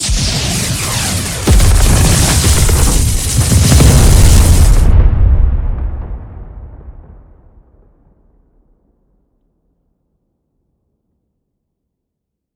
slaser.wav